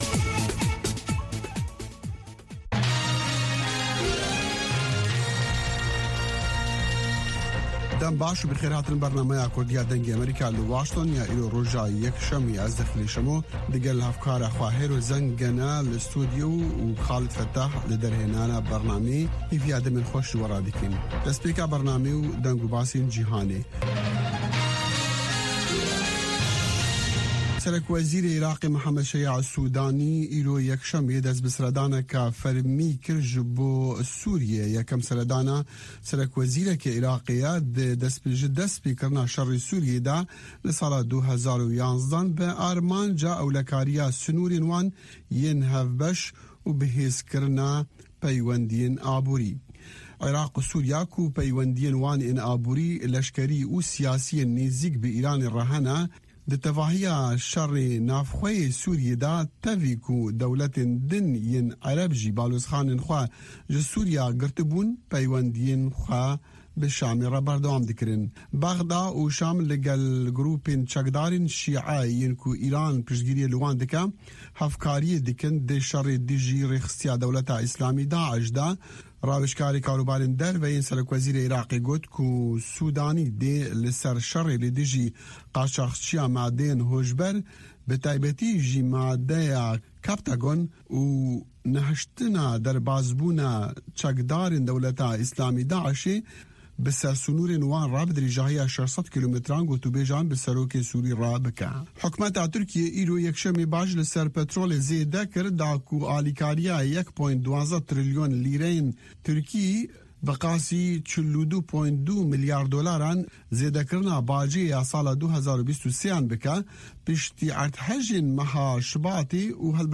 هەواڵەکانی 3 ی پاش نیوەڕۆ
هەواڵە جیهانیـیەکان لە دەنگی ئەمەریکا